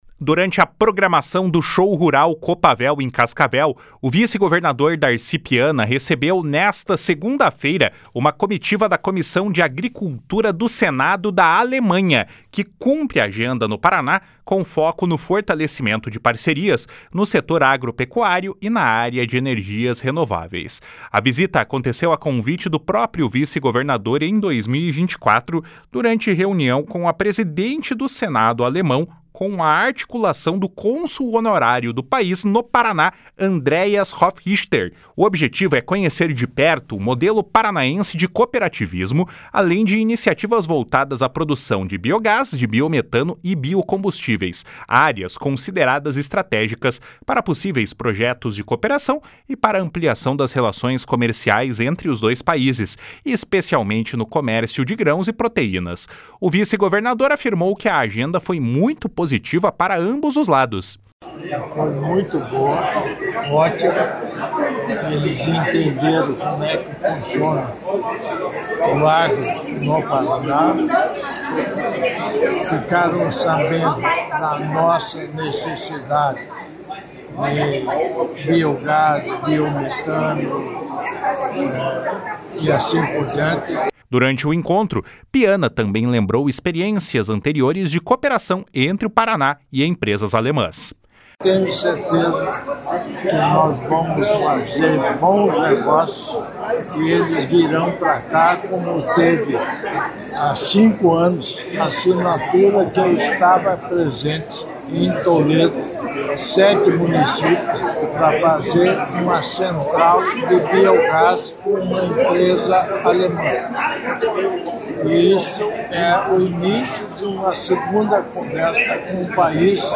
Durante o encontro, Piana também lembrou experiências anteriores de cooperação entre o Paraná e empresas alemãs. // SONORA DARCI PIANA //